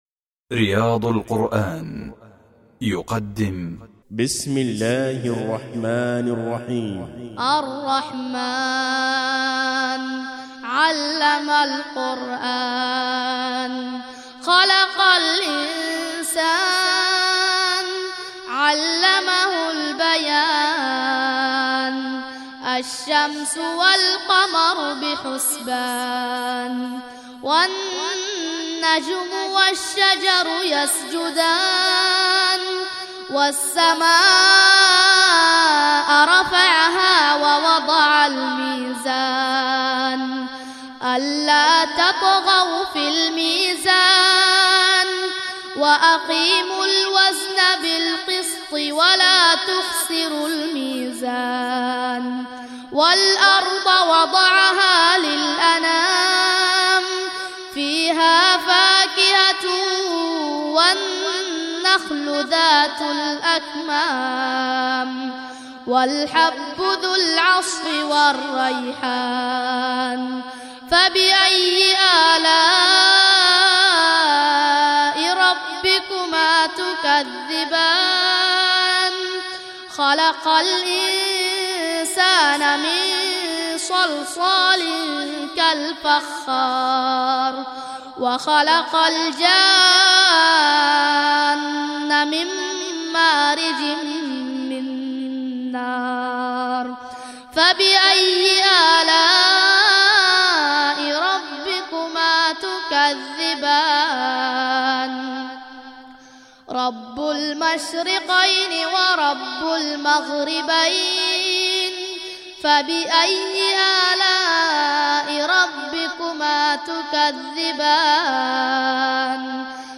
ترتیل سوره الرحمن